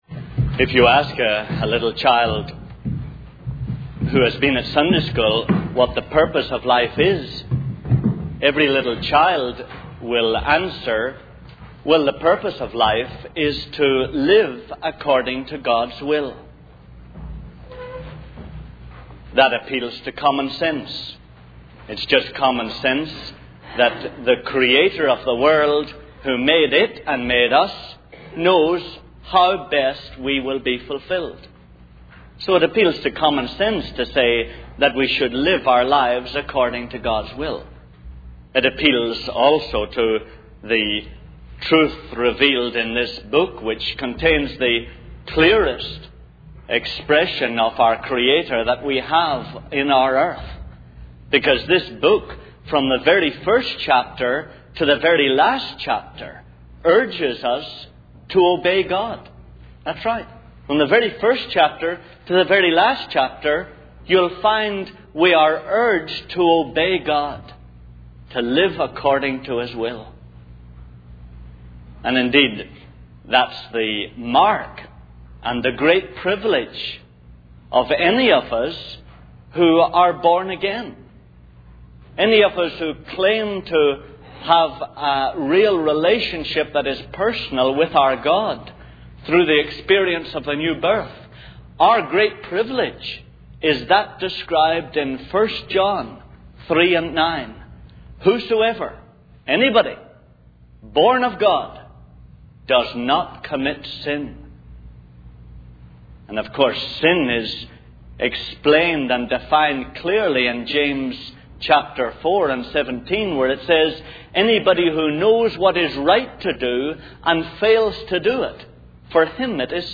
In this sermon, the speaker addresses the struggle many people face in showing their true selves at work and in their personal lives.